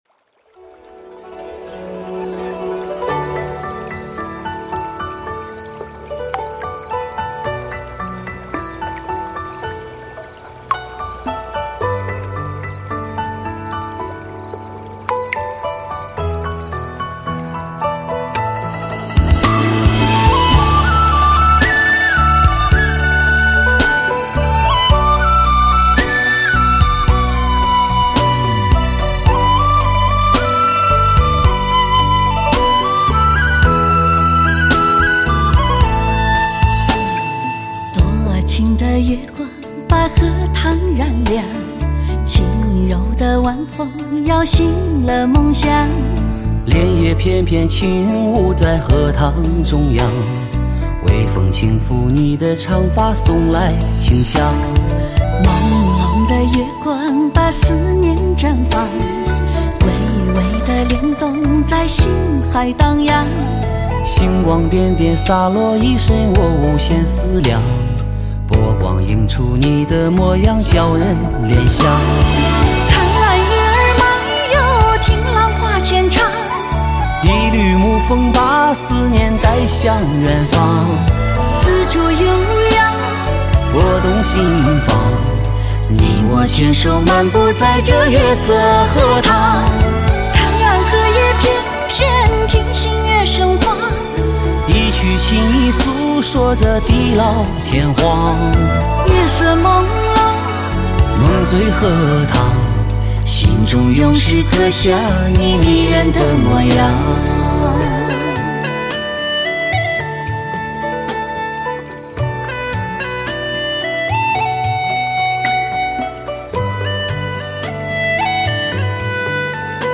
清新的旋律 诗意的歌词 优雅的演绎